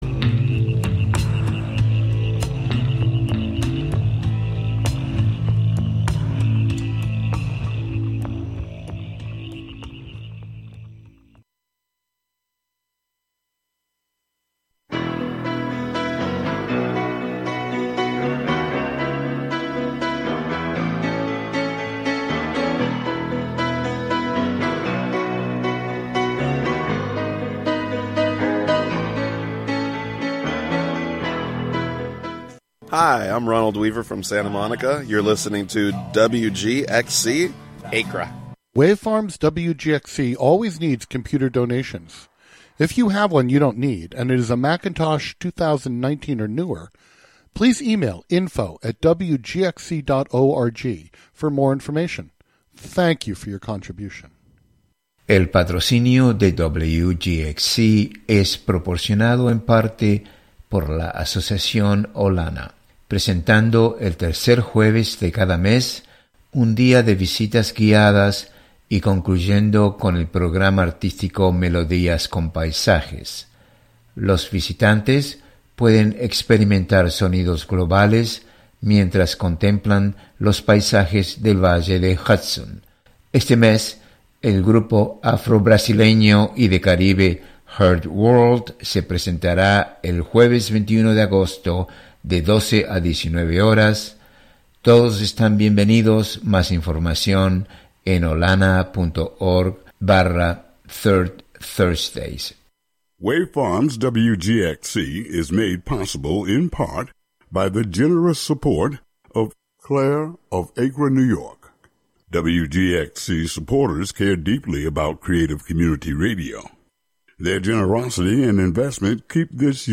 Each show, "The Radio Museum" broadcasts a collection of different voices from radio's past. True raconteurs who knew how to tell intimate, personal, engaging stories on the radio.
Plus some music from the era as well as a weekly recipe.